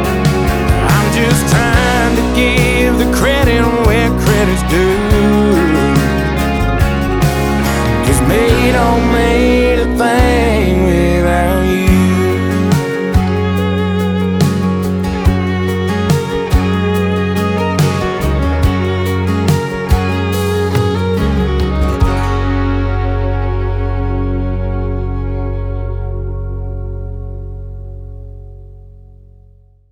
• Country
fiddle